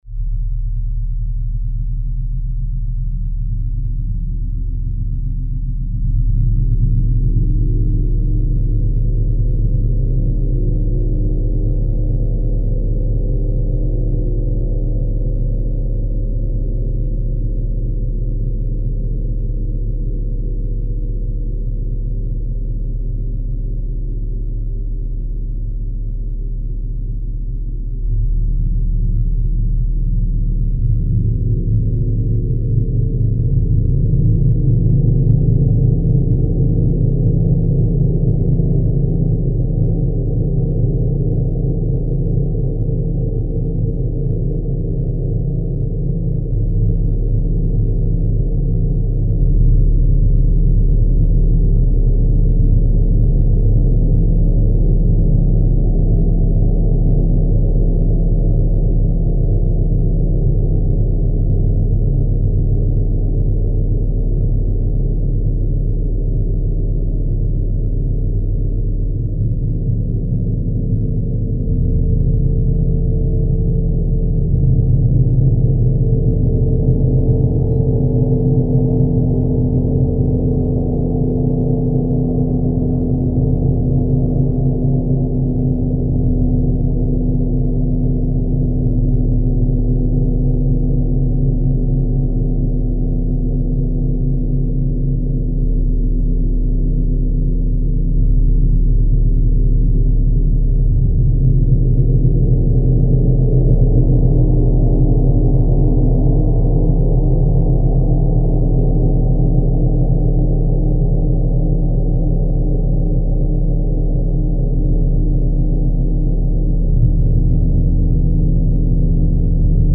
Esta grabación es la real del Gong disponible
Gong Sinfónico 85cm